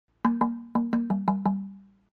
Black Swamp Log Drum - Medium 25"
The Black Swamp LOG2 Drum is the next to smallest of the 4 available and measures 25″ in length with 2 pitches.All Black Swamp log drums are made with a solid maple soundboard, Finnish birch plywood resonator case, and feature precisely CNC cut soundboards and BSP logo on the side.